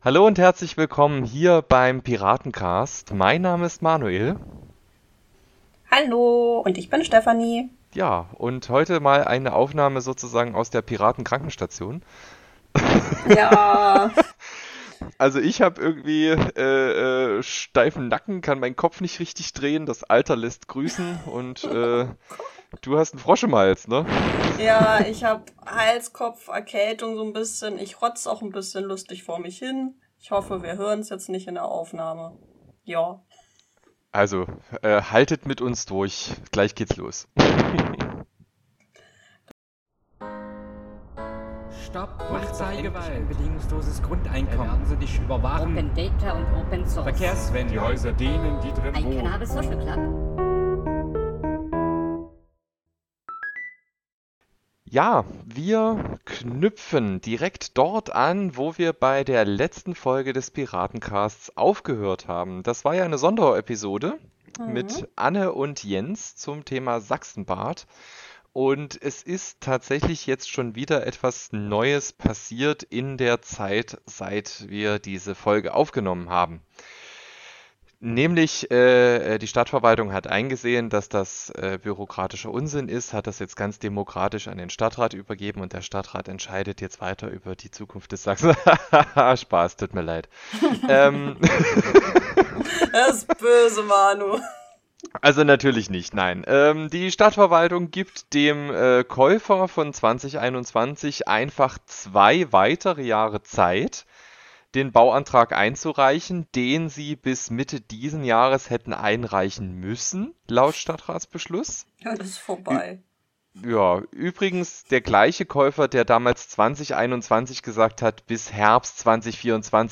Als besonderes Schmankerl gibt's am Ende der Folge unseren Audiospot zur Landtagswahl.